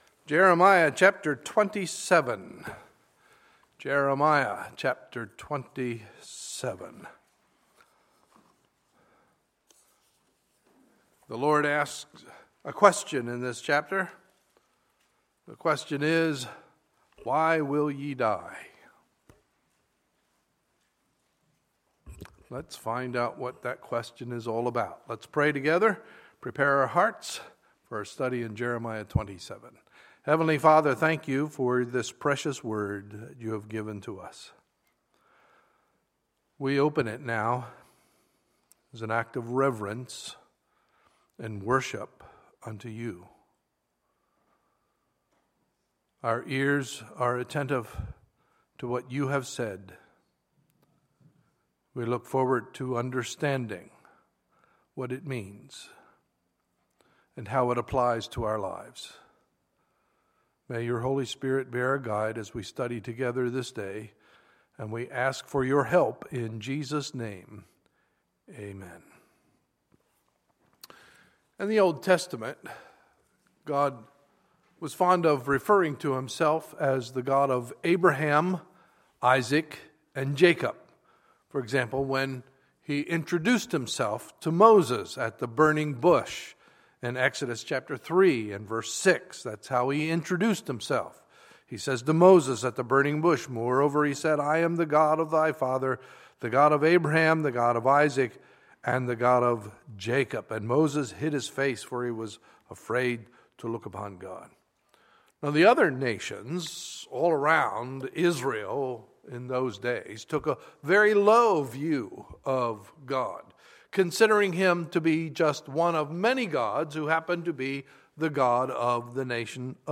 Sunday, September 13, 2015 – Sunday Morning Service